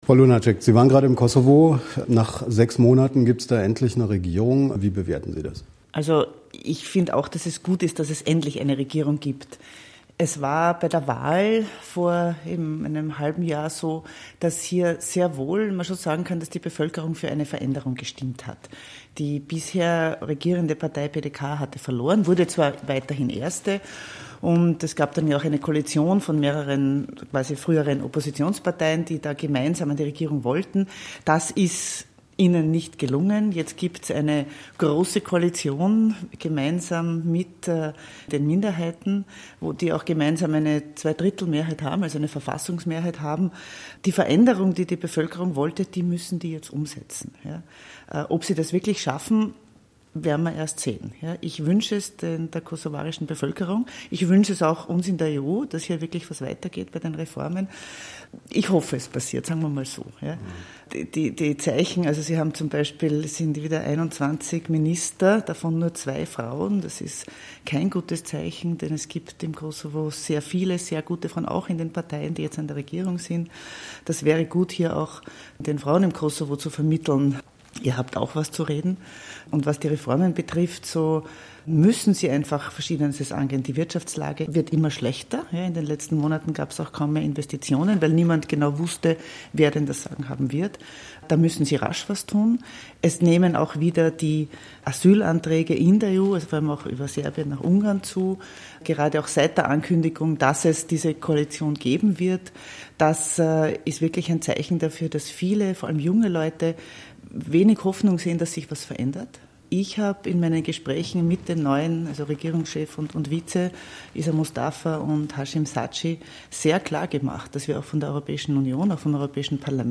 Exklusiv-Interview-Ulrike-Lunacek-Kosovo.mp3